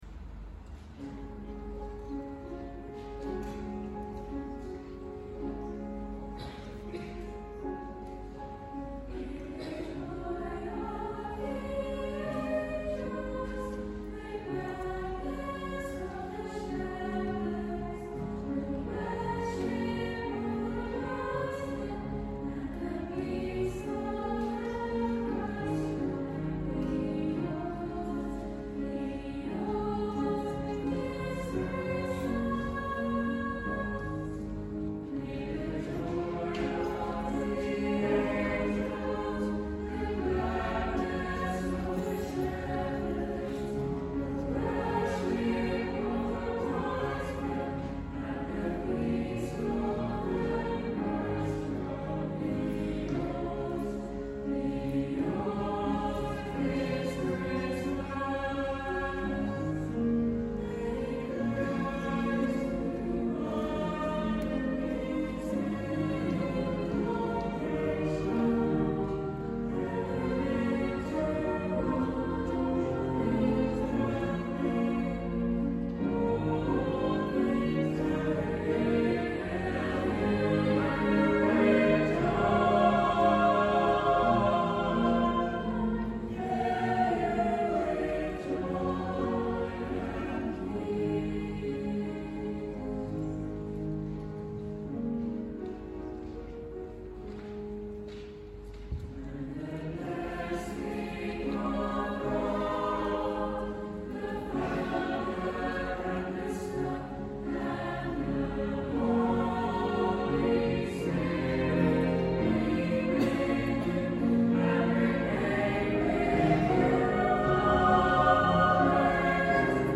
A Christmas Blessing | Chamber Choir